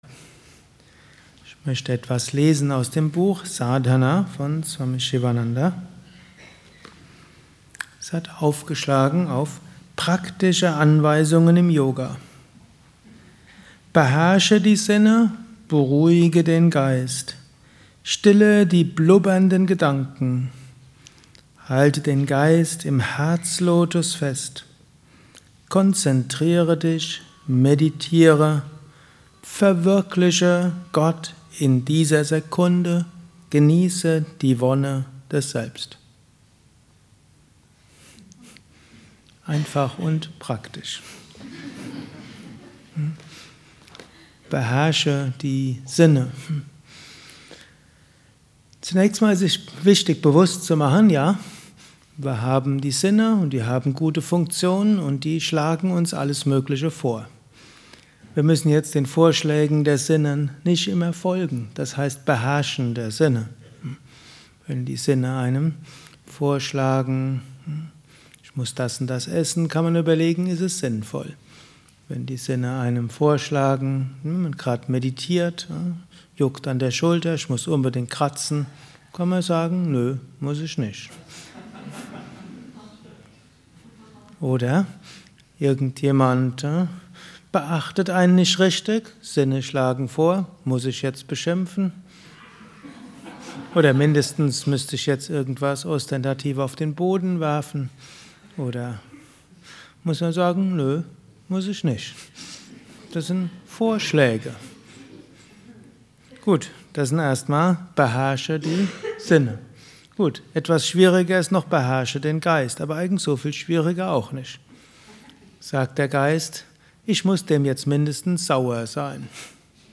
Gelesen im Anschluss nach einer Meditation im Haus Yoga Vidya Bad Meinberg.
Lausche einem Vortrag über: Praktische Anweisungen im Yoga